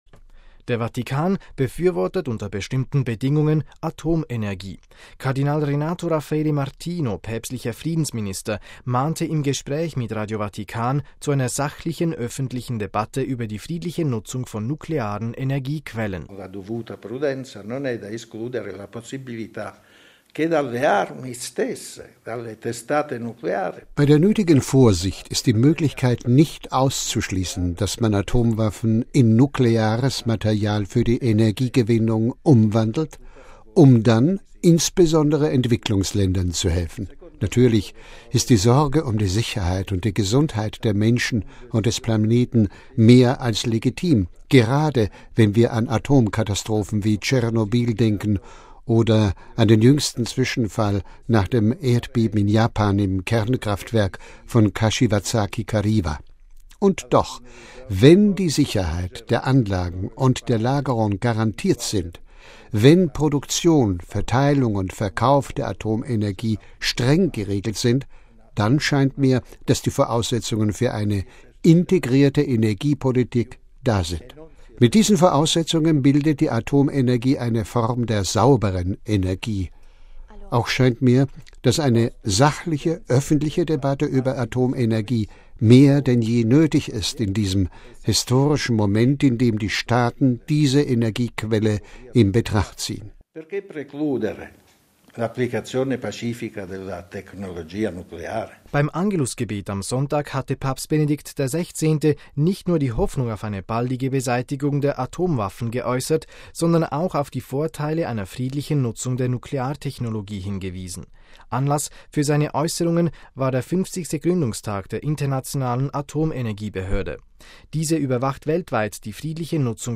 MP3 Der Vatikan befürwortet unter bestimmten Bedingungen Atomenergie. Kardinal Renato Raffaele Martino, päpstlicher „Friedensminister“, mahnte im Gespräch mit Radio Vatikan zu einer „sachlichen öffentlichen Debatte“ über die friedliche Nutzung von nuklearen Energiequellen.